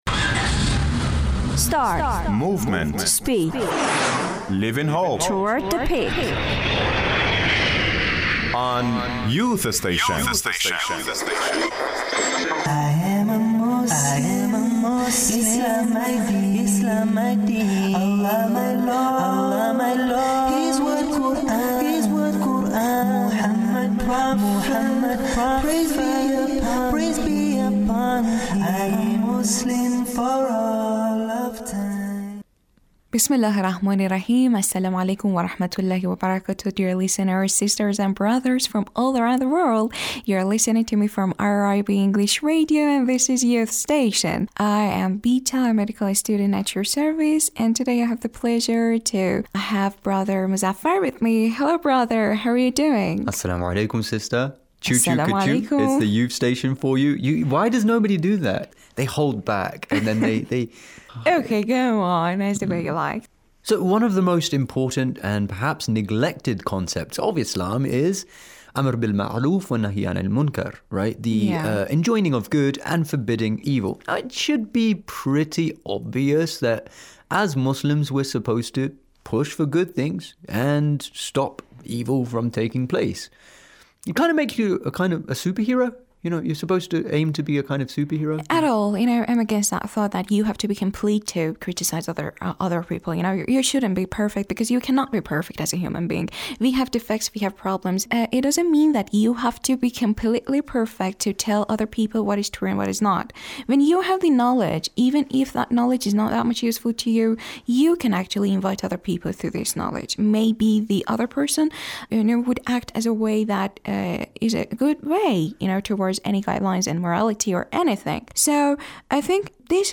Muslim Youth speak about Inviting to the Good Fobidding the Evil